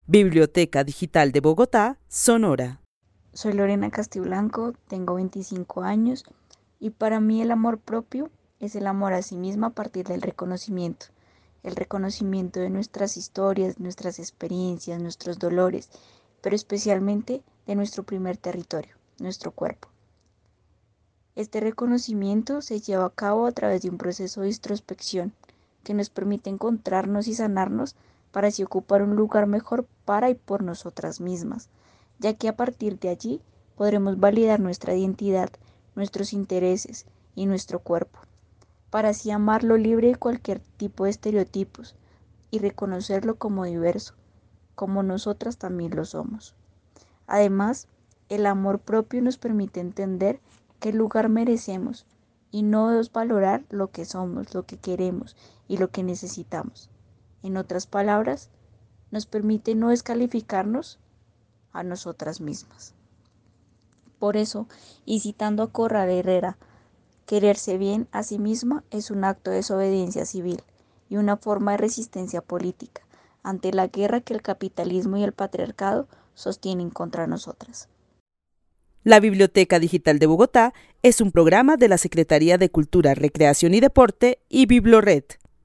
Narración oral de una mujer de 25 años que vive en Bogotá, y para quien el amor propio es el reconocimiento de las historias, el dolor y especialmente del cuerpo como primer territorio de las mujeres. Destaca la importancia de hacer un proceso de introspección, el cual permita sanar y encontrarse.